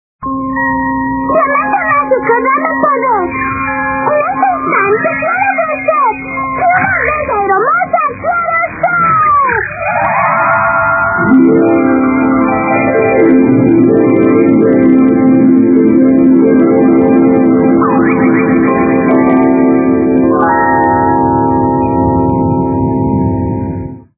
Estos archivos incluyen tanto las voces originales de la serie de TV como las voces del doblaje en español.